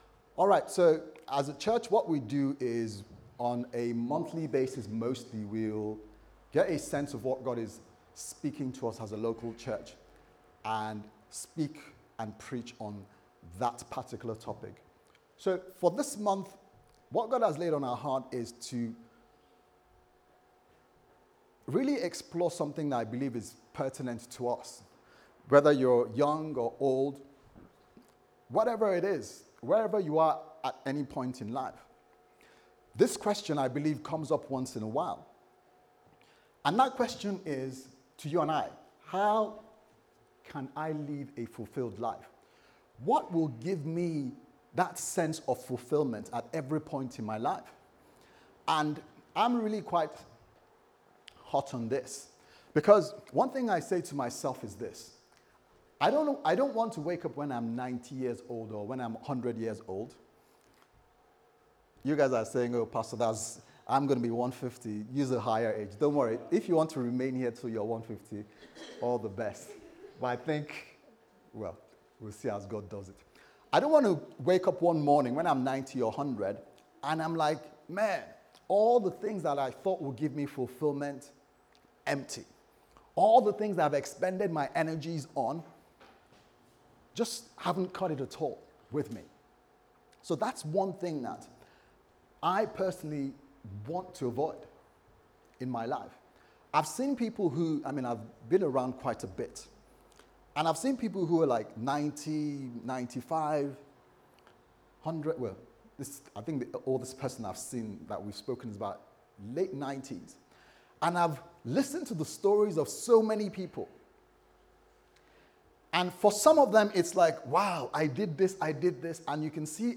Service Type: Sunday Service Sermon